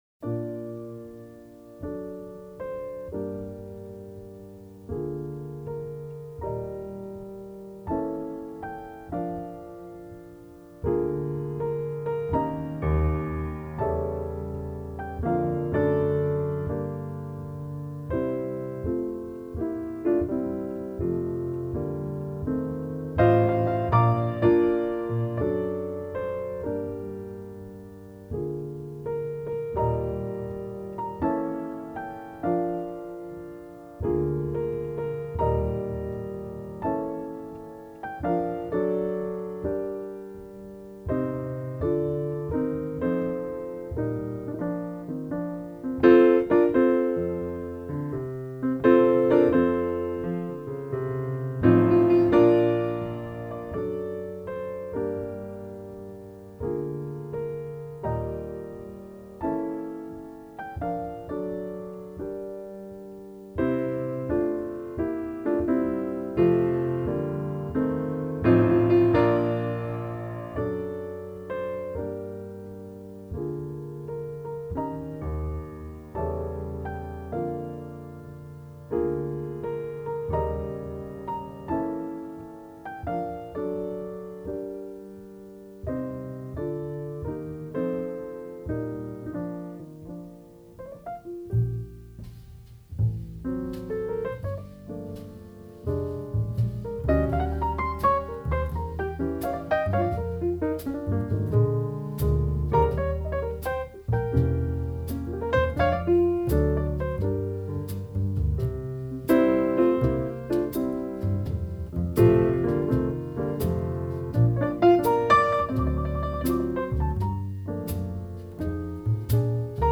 jazz
bass
drums
tremendous cascading rolls of piano